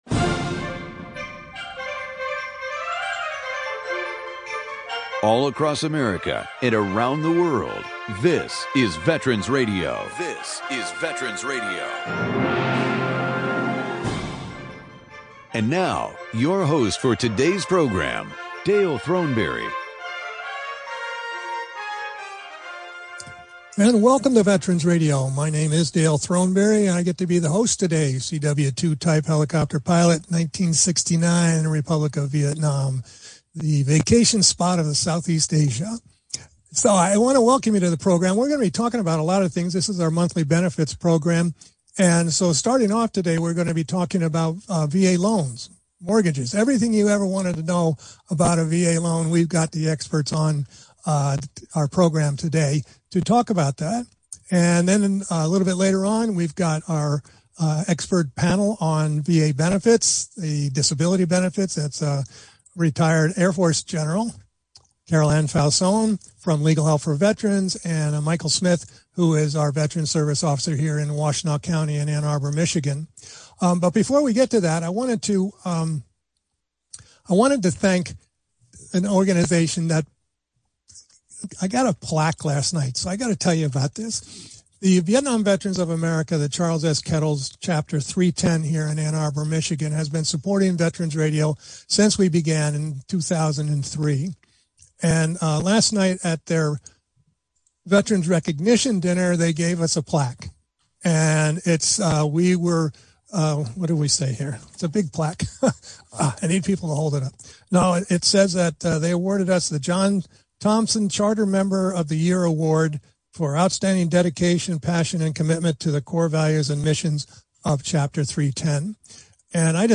live broadcast on WAAM 1600am and WDTK 1400am
Call in during the program to ask your question or offer a comment.